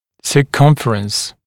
[sə’kʌmfərəns][сэ’камфэрэнс]окружность, замкнутая кривая, длина окружности, длина замкнутой кривой